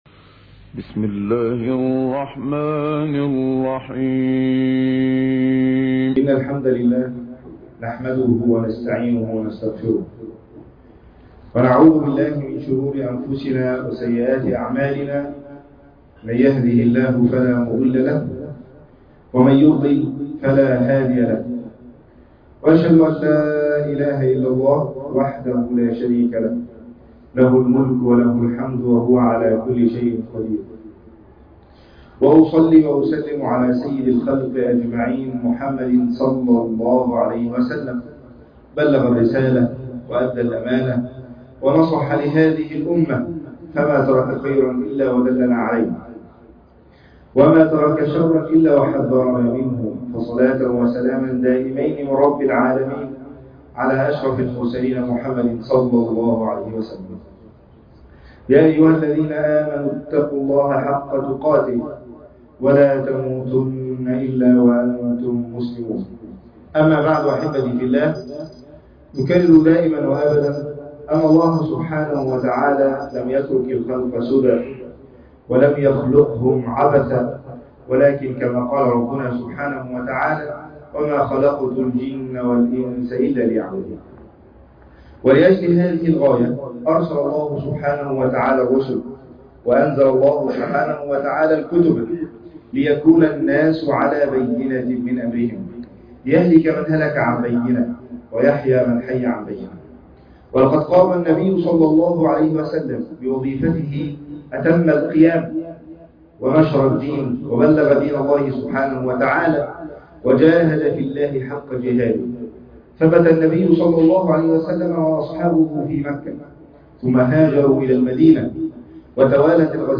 لا تقدموا بين يدي الله ورسوله خطبة جمعة